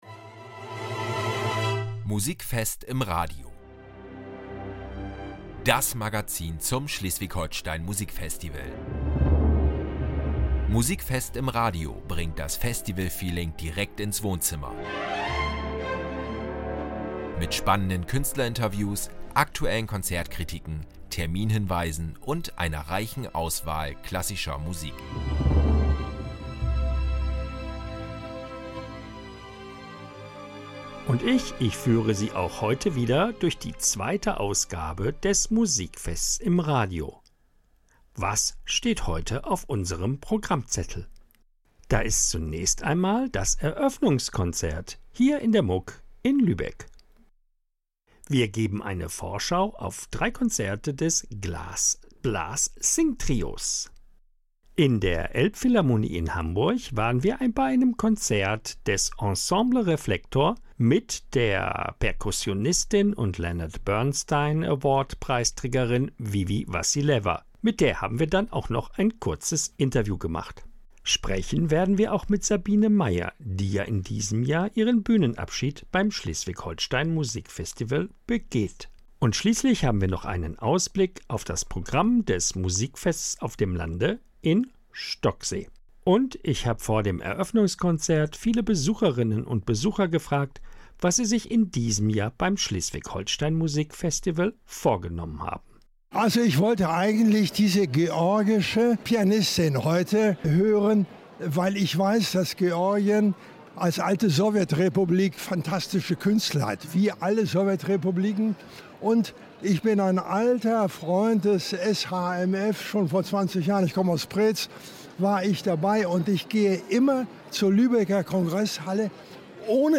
In Folge 2 von »Musikfest im Radio« werfen wir einen Blick zurück und voraus: Wir berichten vom Eröffnungskonzert des Schleswig-Holstein Musik Festivals 2025 - mit einer Publikumsumfrage und einer fundierten Konzertkritik.
Im Interview